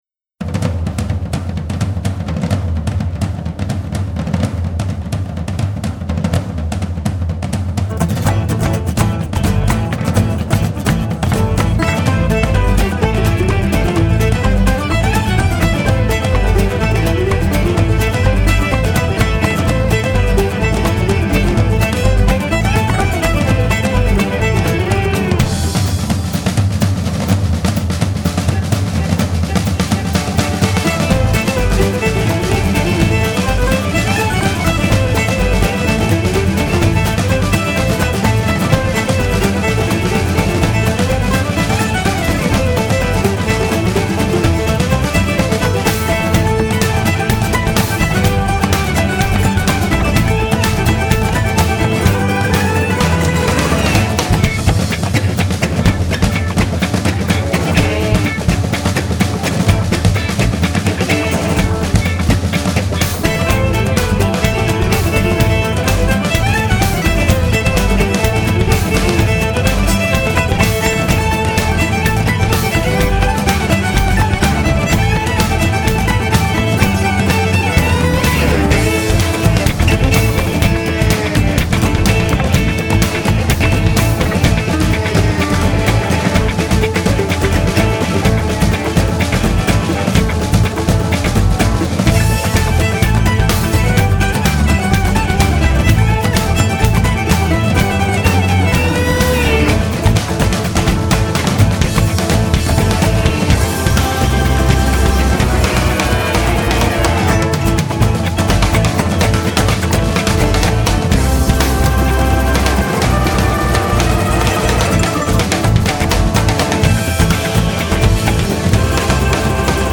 fiddle, mandolin, banjo, slide guitar, bass, and hurdy gurdy